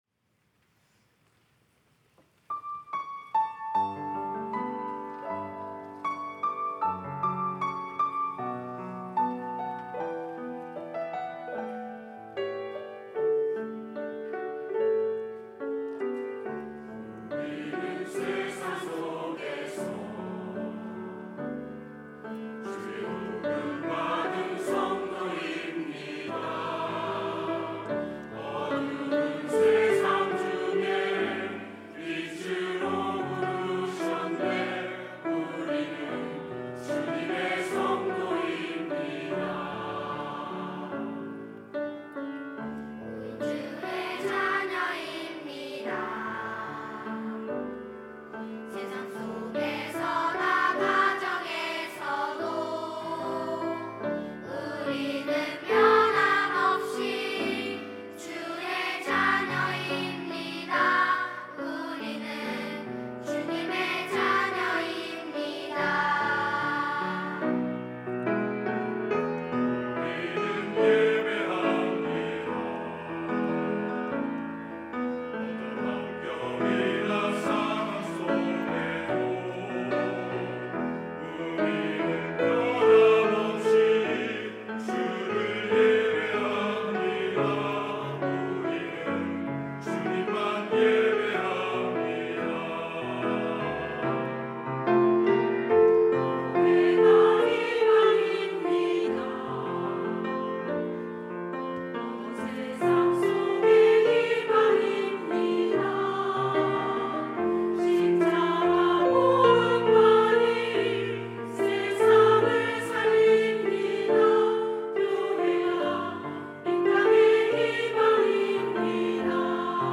전세대 연합찬양대